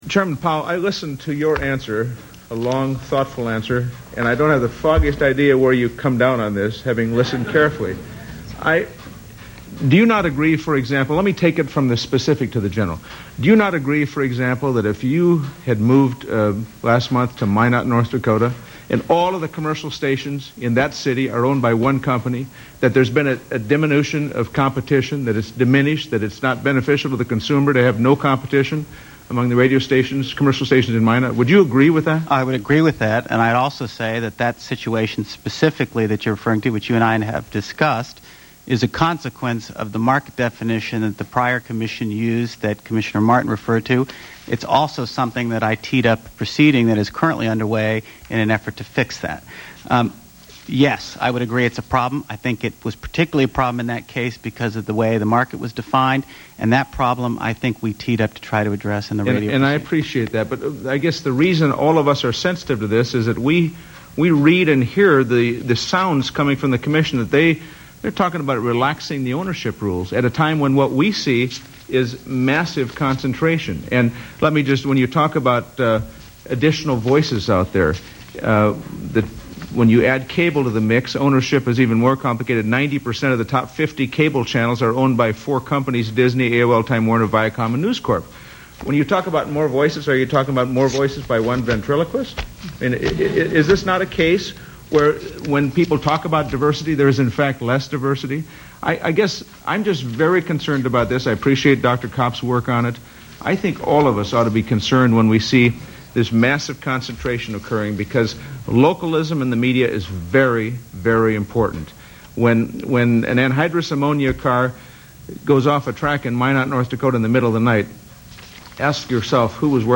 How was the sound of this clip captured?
Anyway, here's a few more choice utterances from the hearing - they are also in MP3 format: